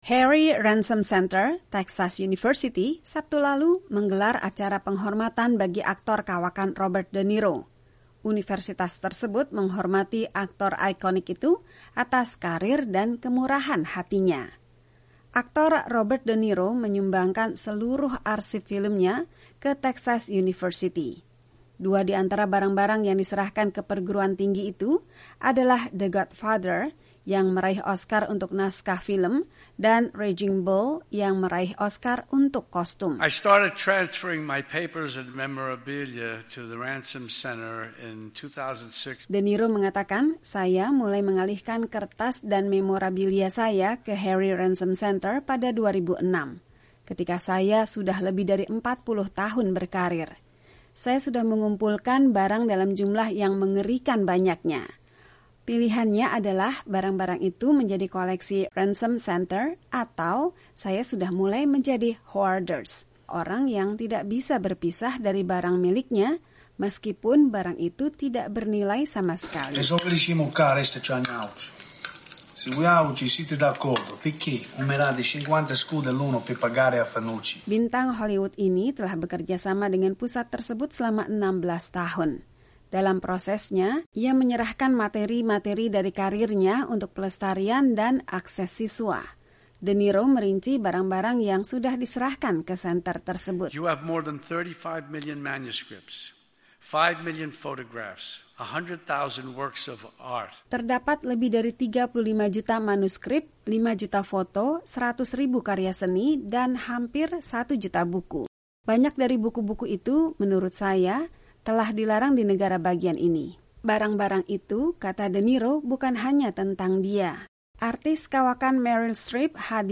Aktris Meryl Streep Beri pidato untuk Hormati Aktor Robert De Niro
Harry Ransom Center, Texas University, pada Sabtu (25/9) lalu menggelar acara penghormatan bagi aktor kawakan Robert De Niro.